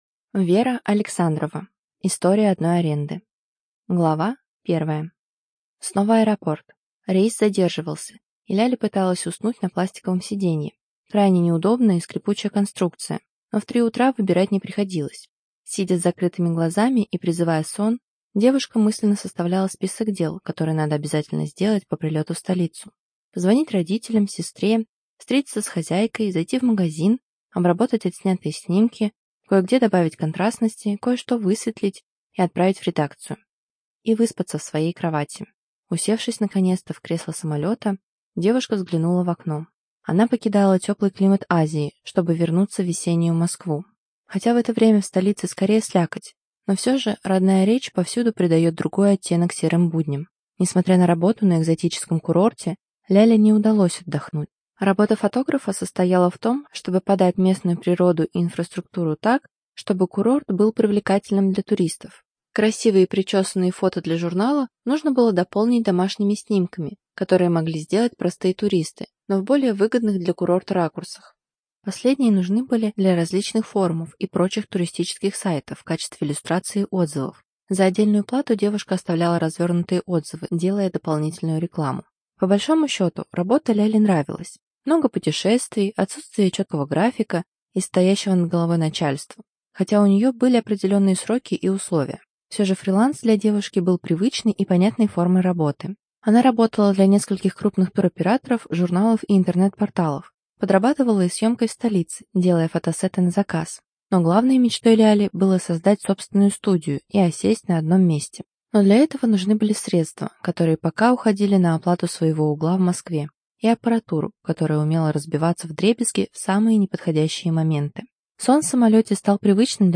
ЖанрЛюбовная проза